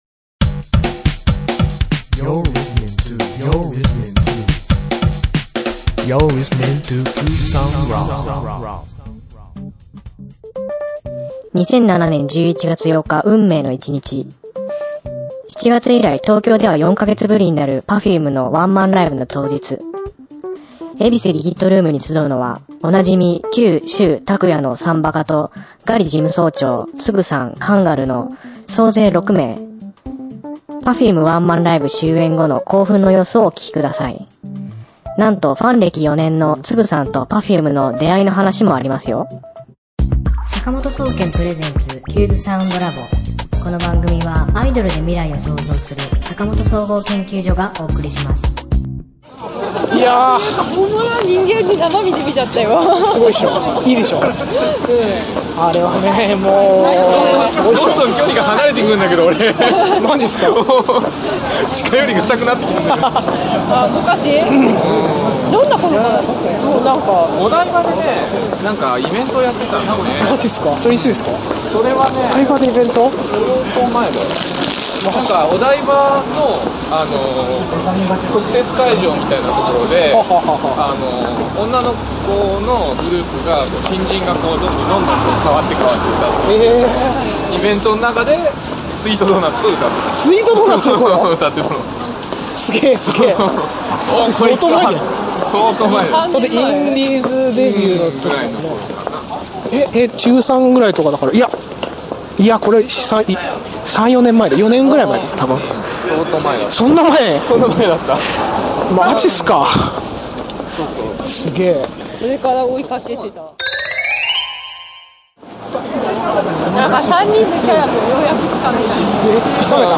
今週のテーマ：Perfumeワンマンライブ、興奮のレポートです！